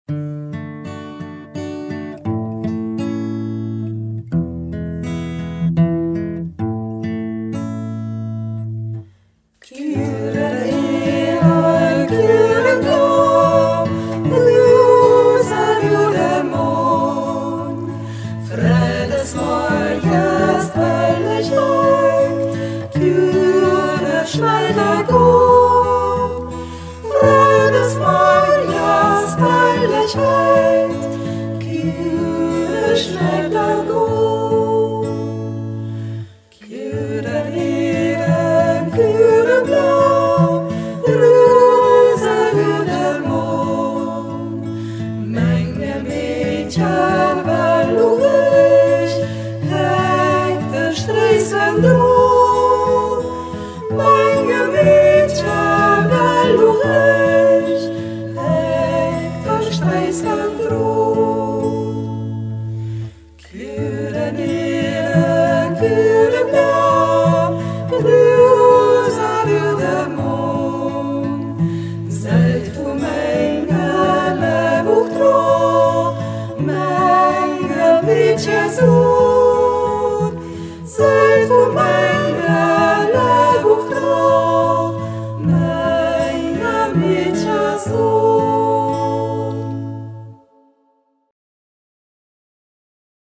Ortsmundart: Mediasch